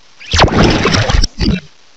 sovereignx/sound/direct_sound_samples/cries/polteageist.aif at 5119ee2d39083b2bf767d521ae257cb84fd43d0e